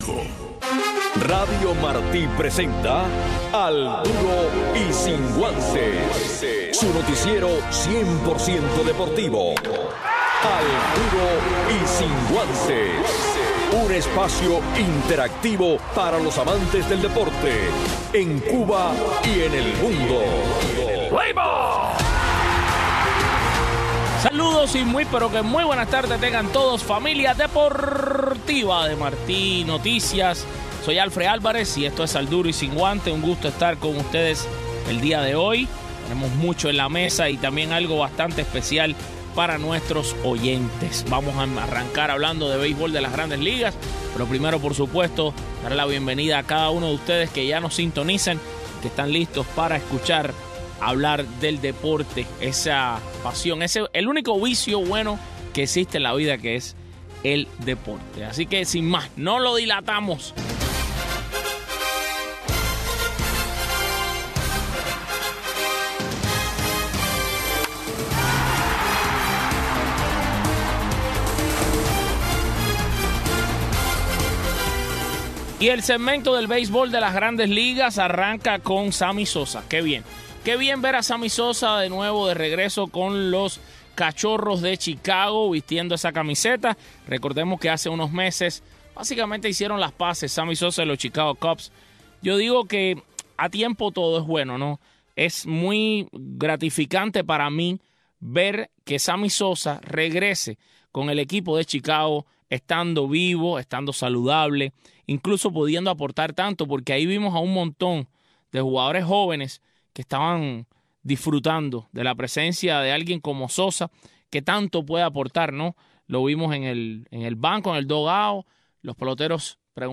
Un resumen deportivo en 60 minutos conducido